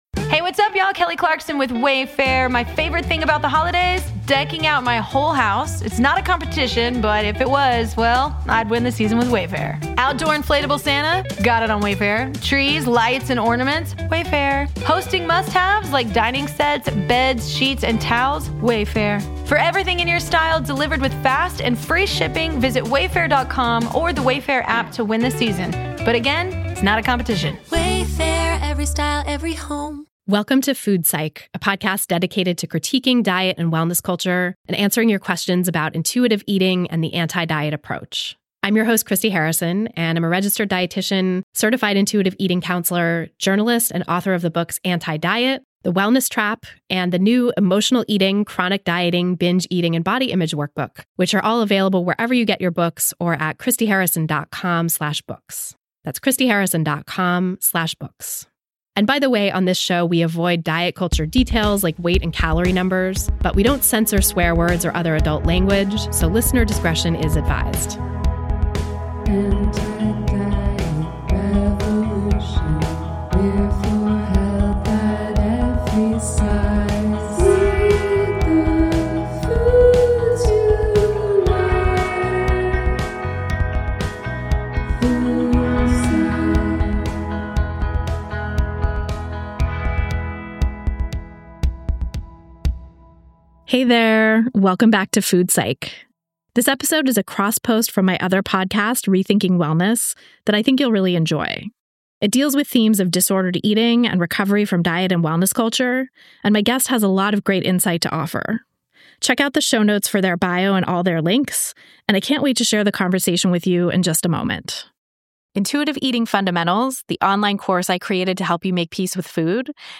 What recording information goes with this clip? This interview was recorded at GOTO Copenhagen 2024.